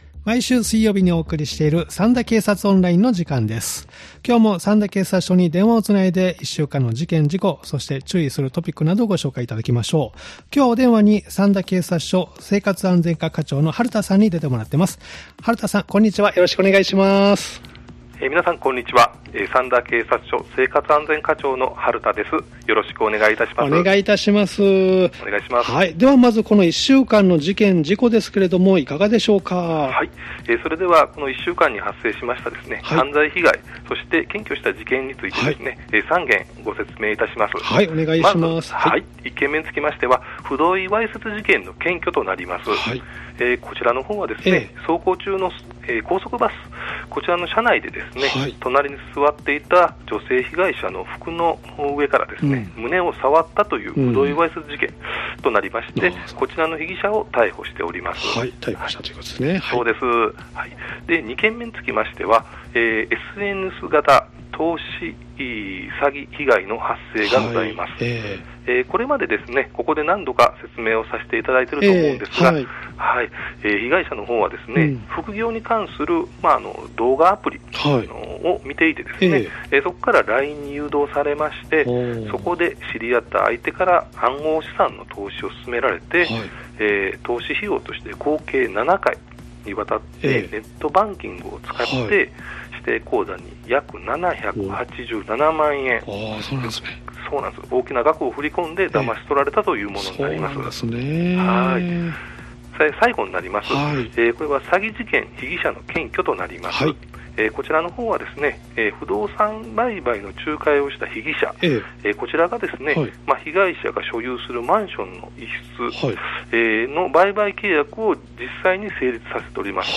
三田警察署に電話を繋ぎ、三田で起きた事件や事故、防犯情報、警察からのお知らせなどをお聞きしています（再生ボタン▶を押すと番組が始まります）